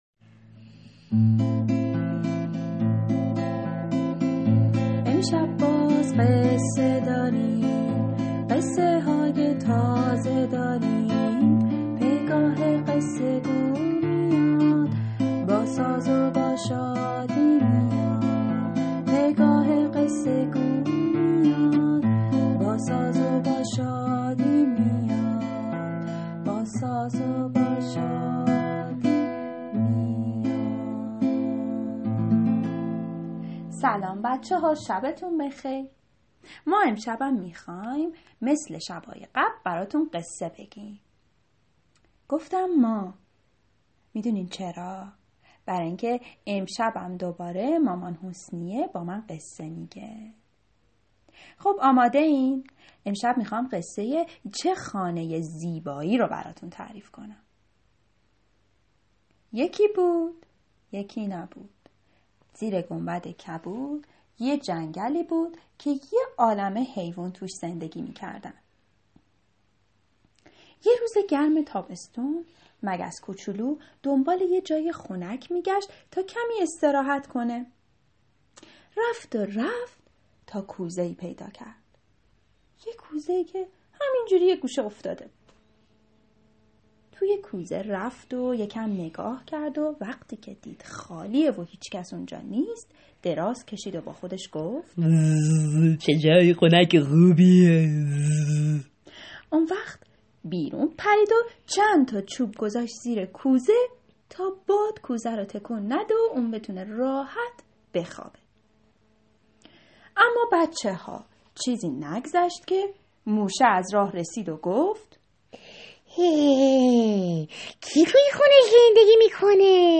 قصه کودکانه صوتی چه خانه ی زیبایی